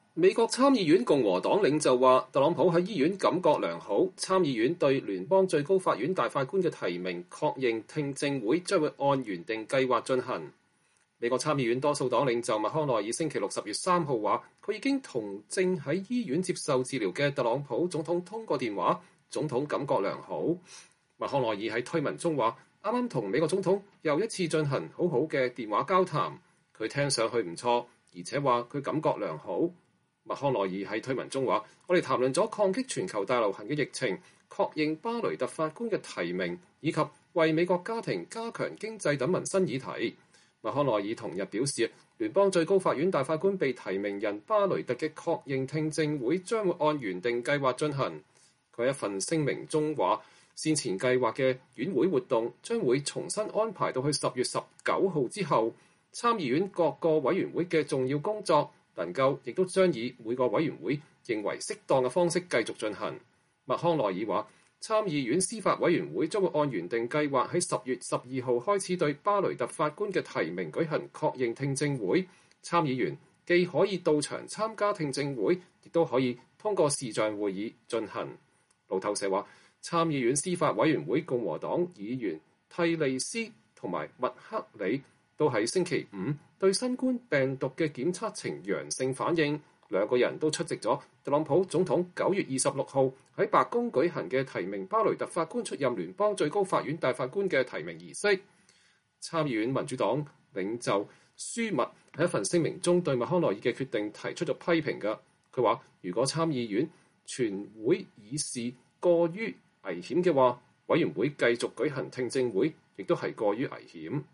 美國參議院多數黨領袖麥康奈爾9月22日在國會對媒體發表講話。